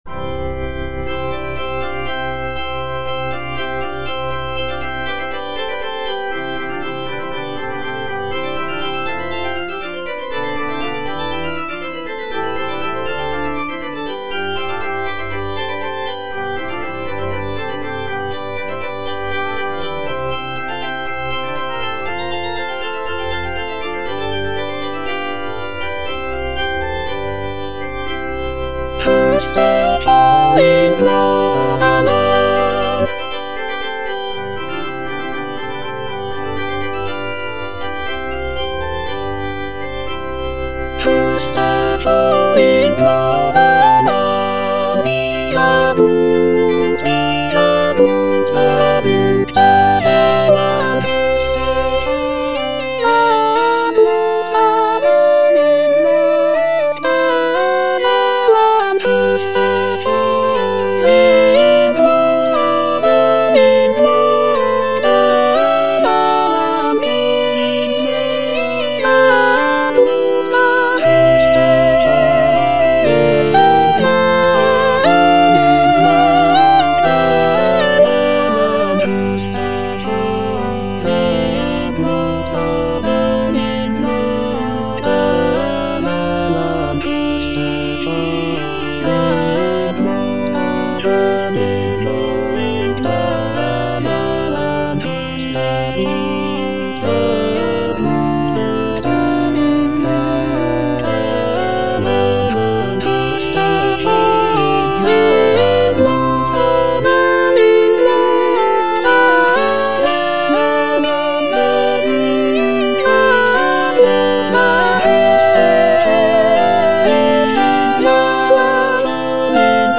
Choralplayer playing Cantata
Soprano (Emphasised voice and other voices) Ads stop